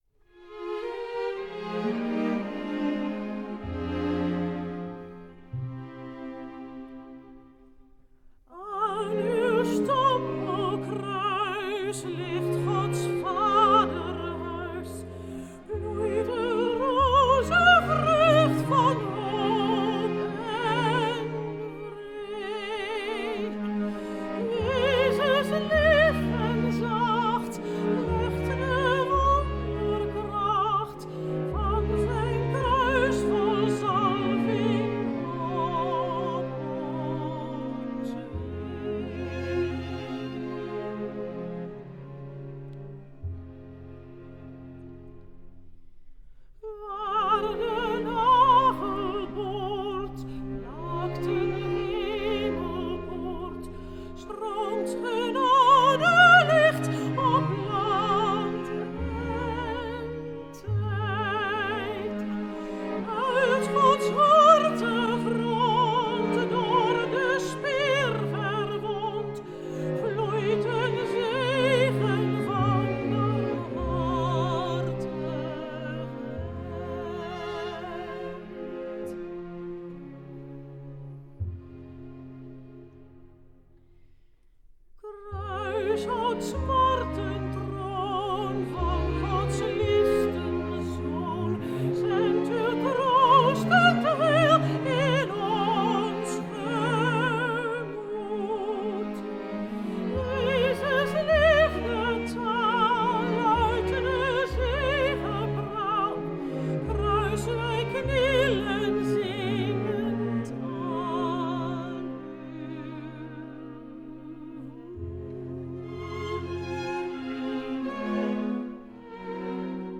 Zang 12